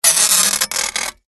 На этой странице собраны разнообразные звуки поворота головы – от легкого шелеста до выраженного хруста.
Скрипящий звук при повороте головы